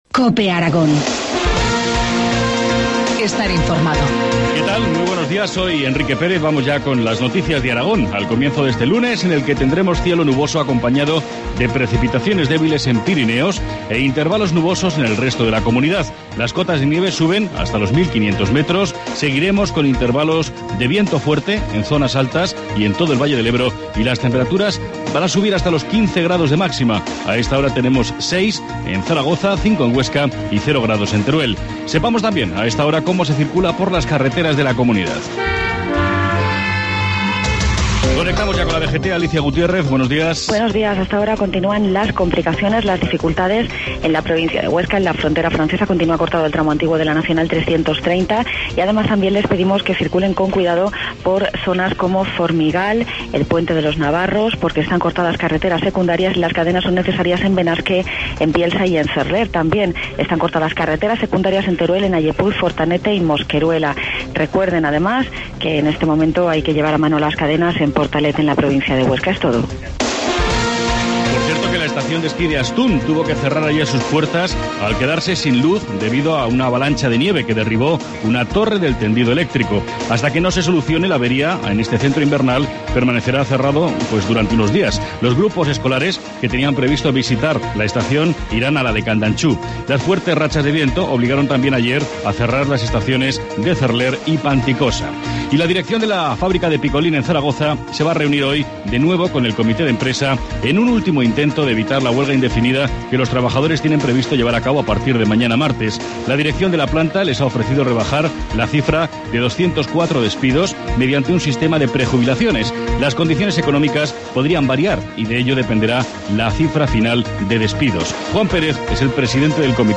Informativo matinal, lunes 4 de febrero, 7.25 horas